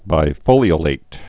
(bī-fōlē-ə-lāt, -lĭt)